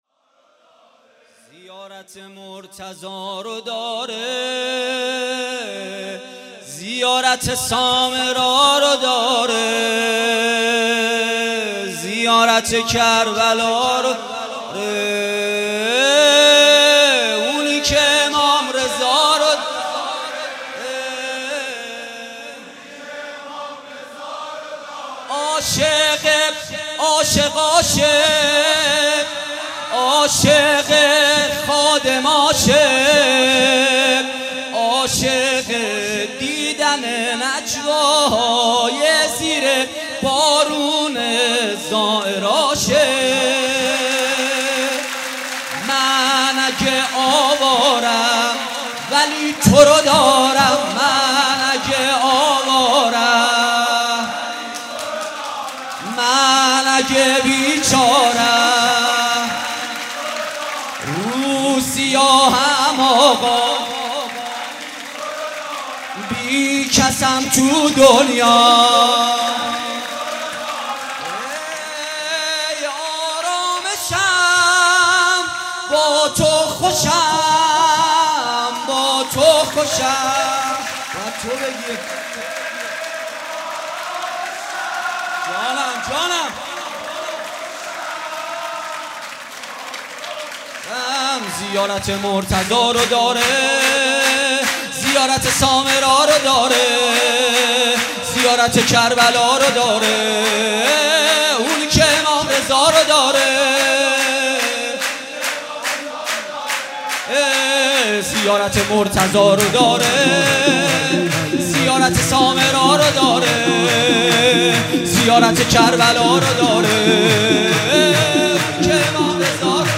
صوت/ مولودی امام رضا (ع) با نوای پویانفر